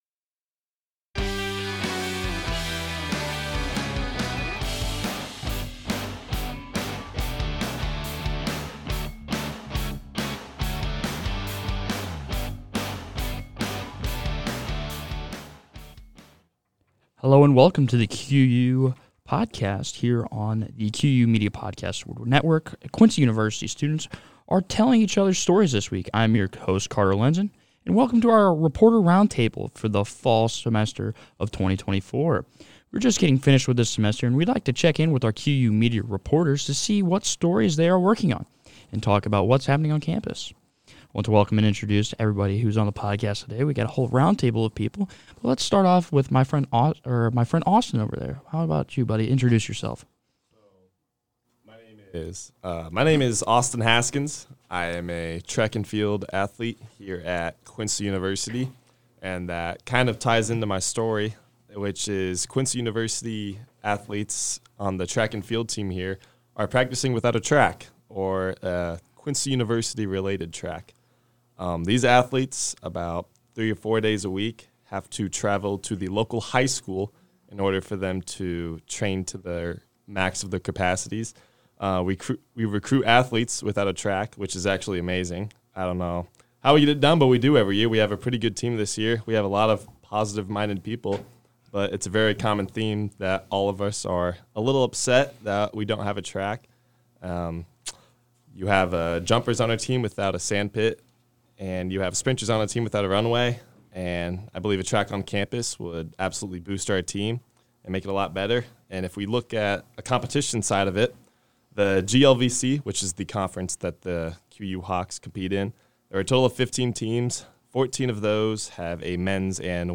Reporters talk about their final stories of the semester, with ideas for how to fix some of the issues plaguing commuters, athletes, and students with cars.